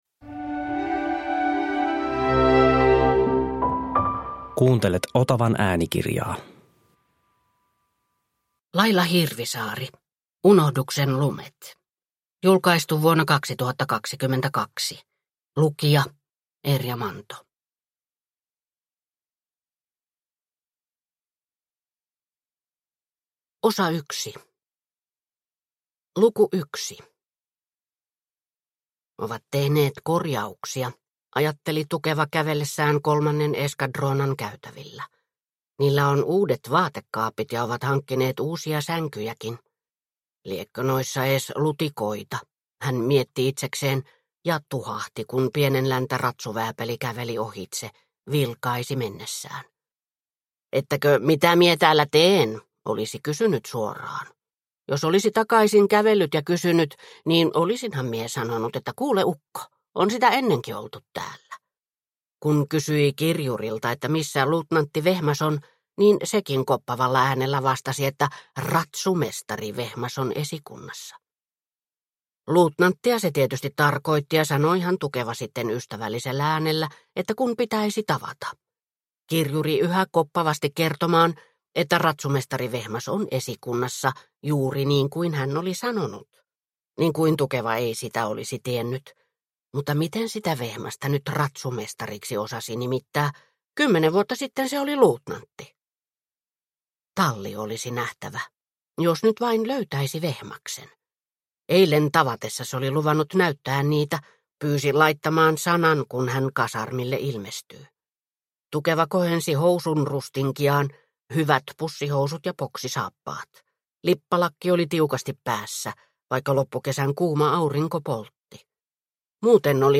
Unohduksen lumet – Ljudbok – Laddas ner